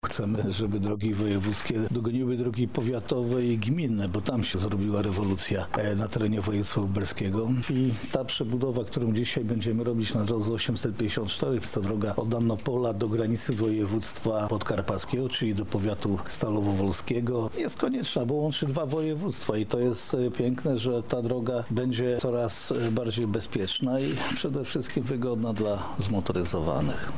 – mówi marszałek Jarosław Stawiarski